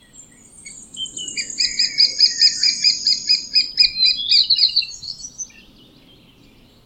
kleine bonte specht
🔭 Wetenschappelijk: Dendrocopos minor
♪ contactroep
kleine_bonte_specht_roep.mp3